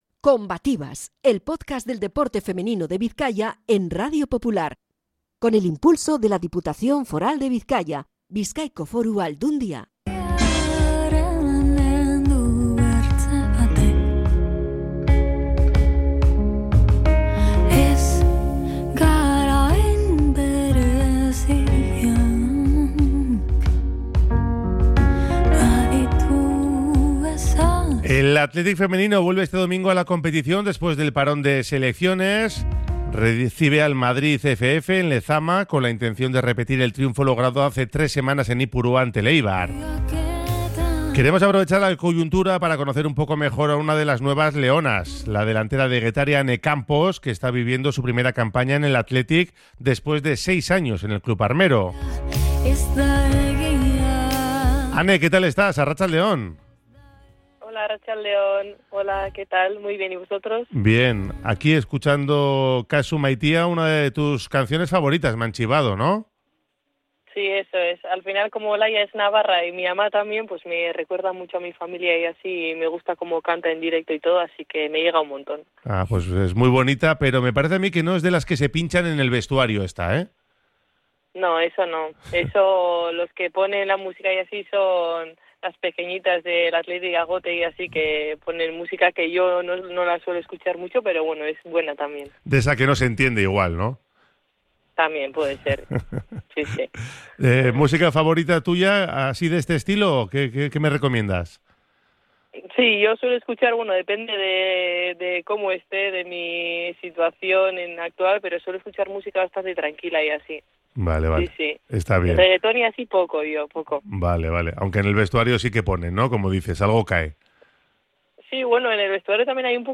Entrevista con la delantera rojiblanca antes de retomar la competición este domingo en Lezama recibiendo al Madrid CFF